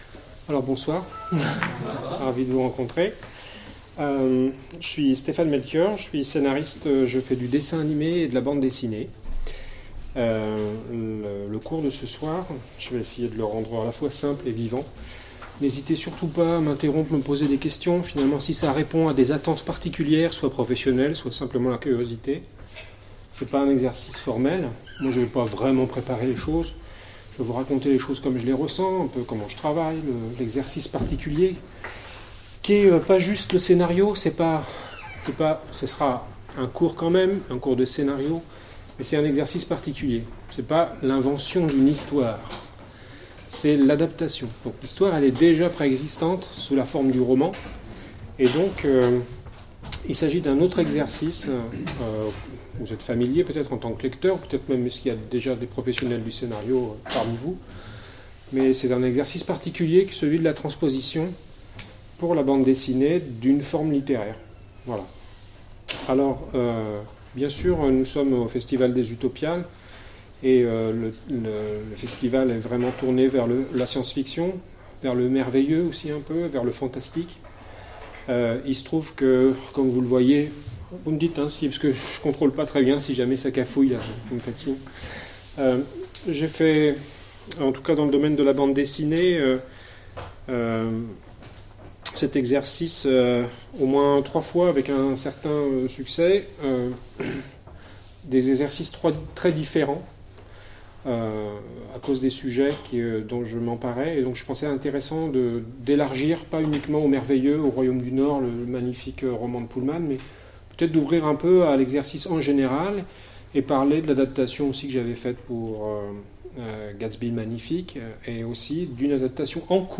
Utopiales 2015
Conférence